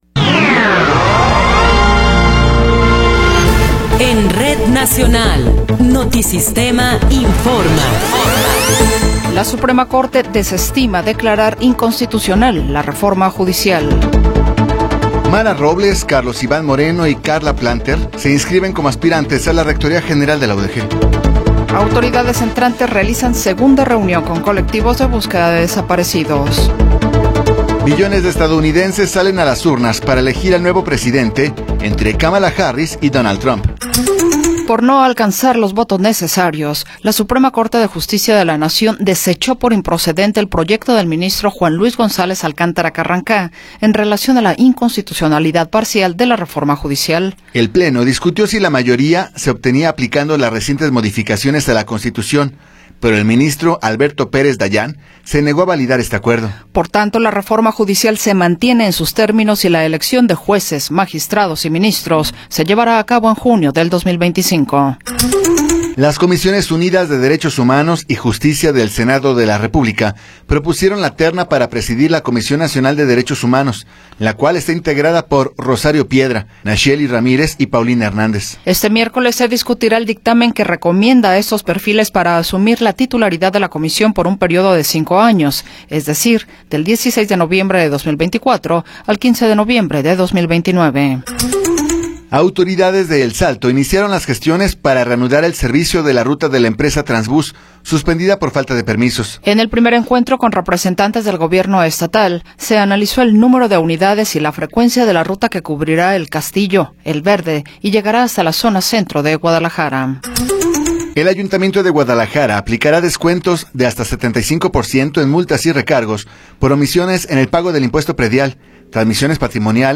Noticiero 20 hrs. – 5 de Noviembre de 2024